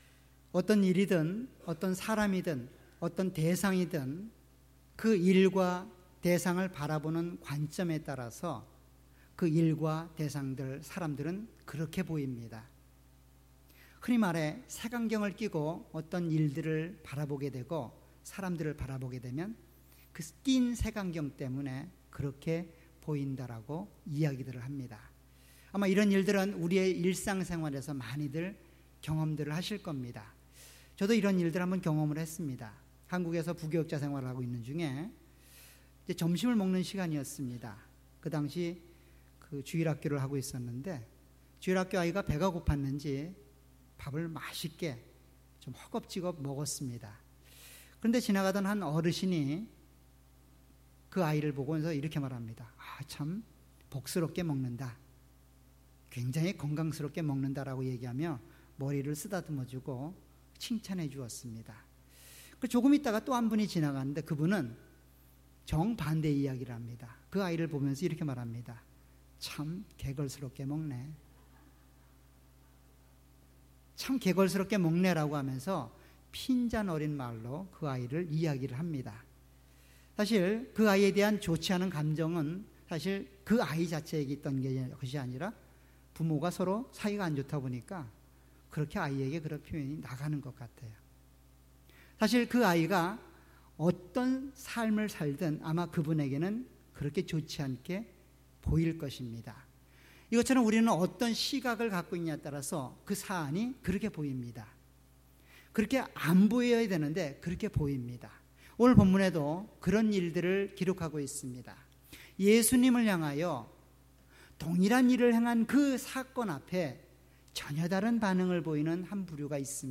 주일예배.Sunday